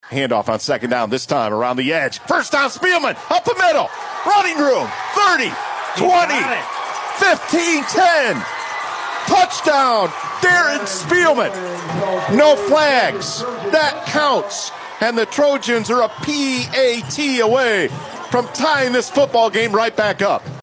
(audio courtesy of Fox Sports Radio 99.7 FM/1230 AM)